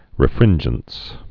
(rĭ-frĭnjəns)